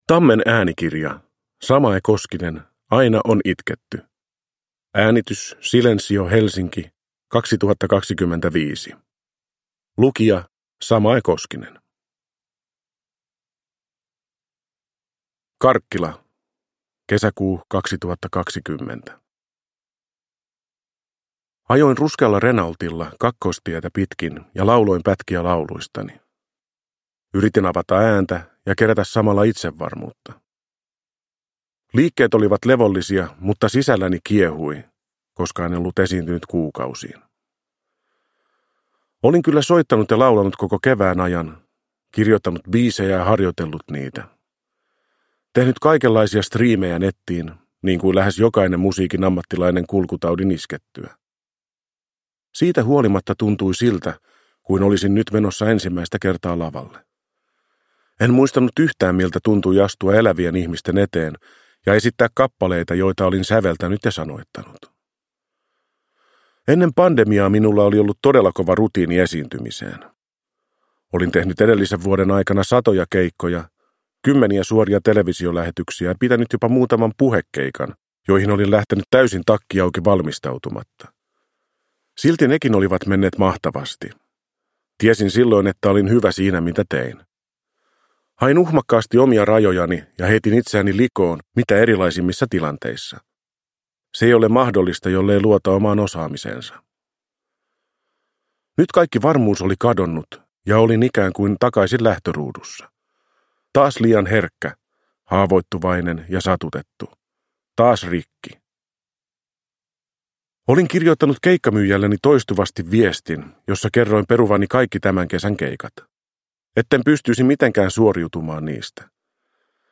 Aina on itketty – Ljudbok
Uppläsare: Samae Koskinen